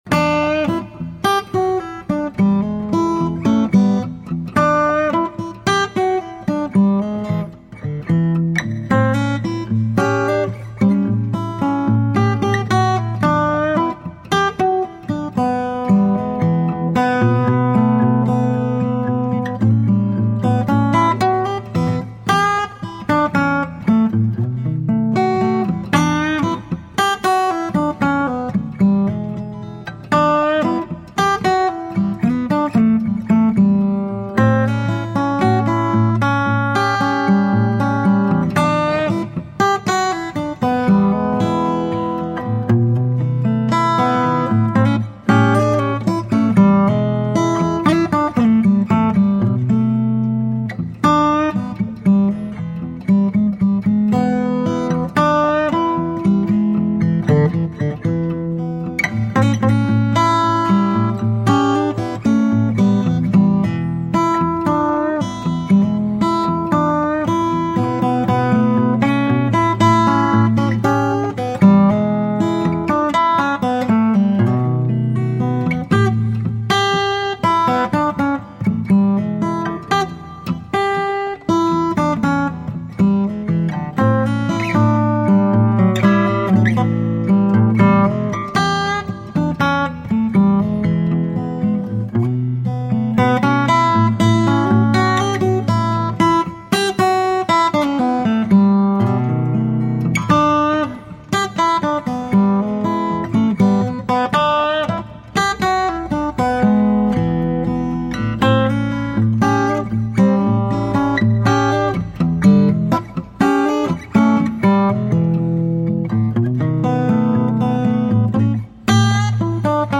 Bluesy and jazzy guitar.
Tagged as: Jazz, Blues, Instrumental Jazz, Acoustic Guitar